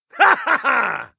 laughter_05